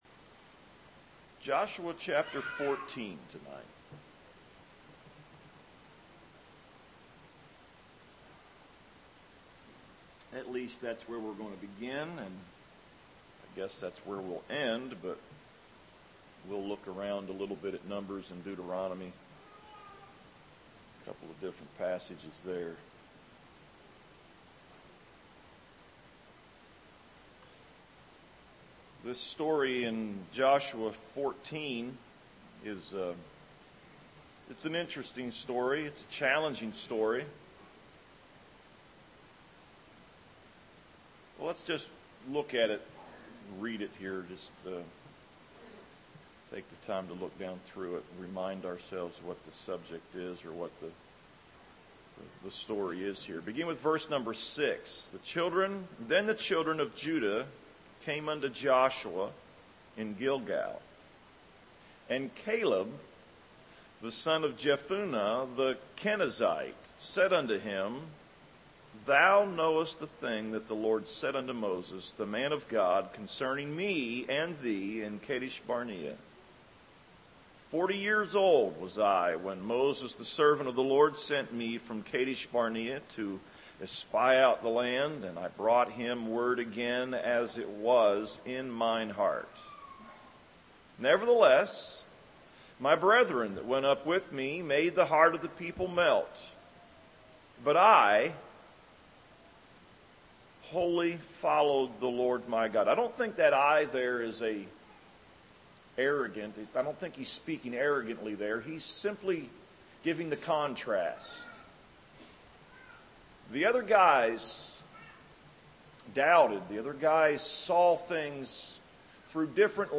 A message